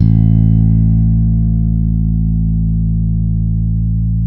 -MM JAZZ A 2.wav